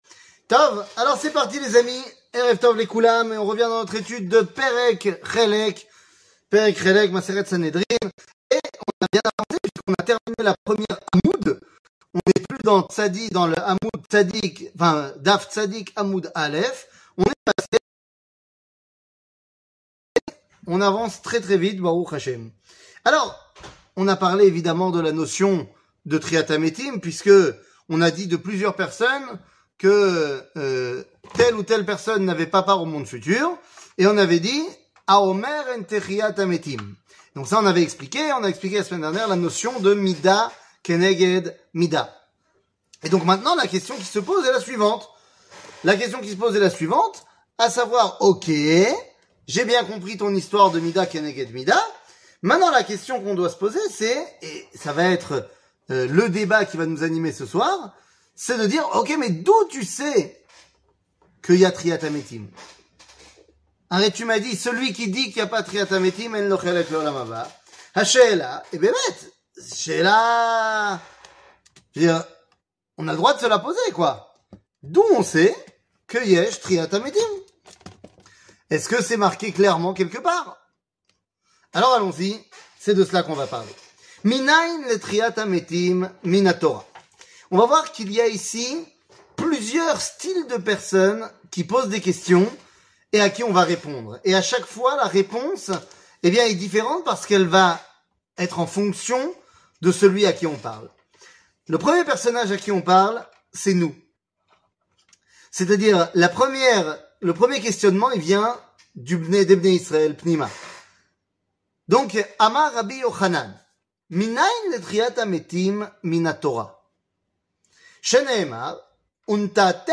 Perek Helek, 04, La resurection des morts 00:41:13 Perek Helek, 04, La resurection des morts שיעור מ 25 אוקטובר 2021 41MIN הורדה בקובץ אודיו MP3 (37.72 Mo) הורדה בקובץ וידאו MP4 (232.41 Mo) TAGS : שיעורים קצרים